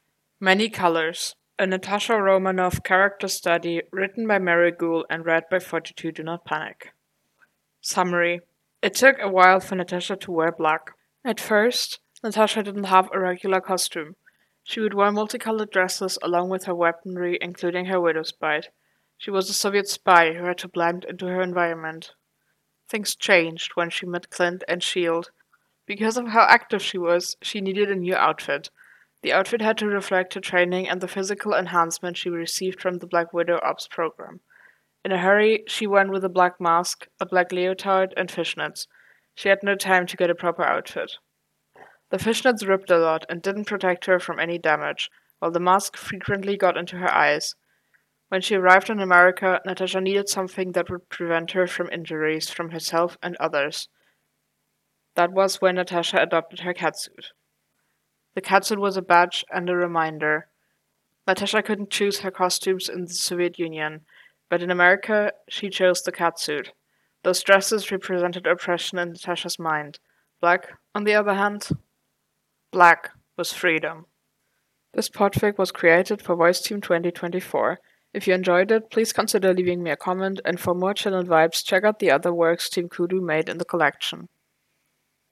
info: collaboration|ensemble